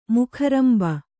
♪ mukharamba